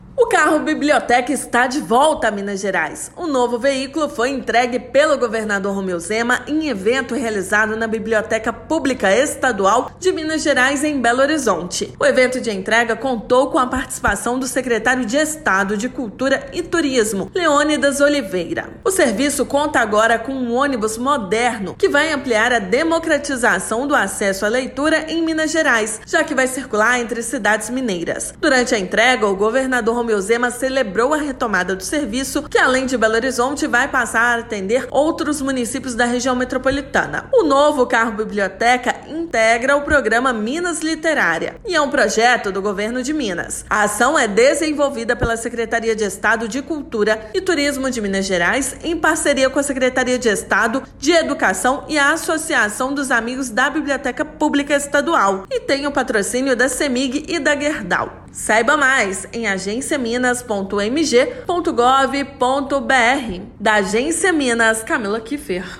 Serviço que contribui para a democratização da leitura no estado voltará a funcionar a partir deste mês. Ouça matéria de rádio.